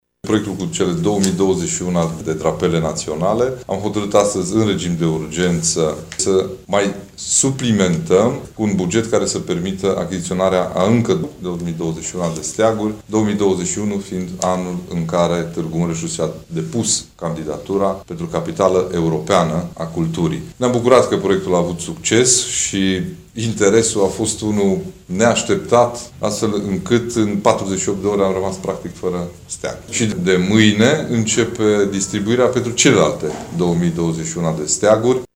Primul lot de 2021 de steaguri s-a epuizat în 24 de ore, a remarcat președintele CJ Mureș, Ciprian Dobre: